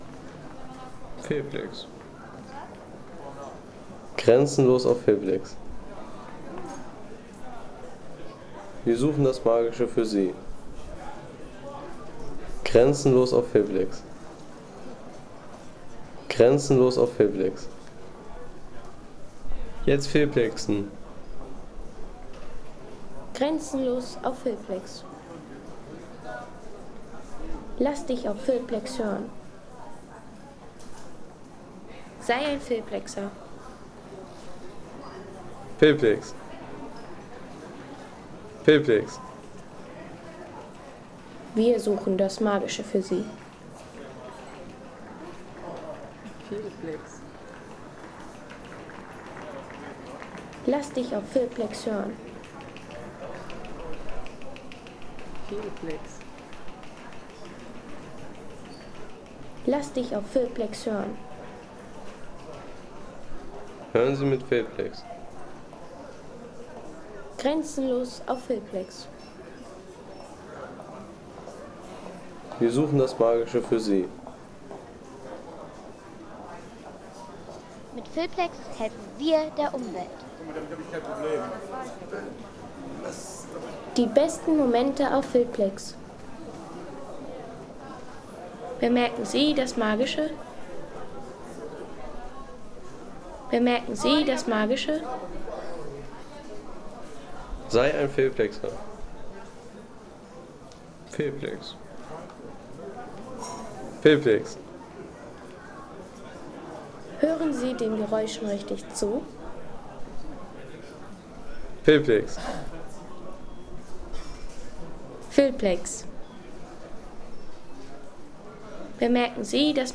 Düsseldorfer Flughafen
Die lebendige Atmosphäre eines Flughafens.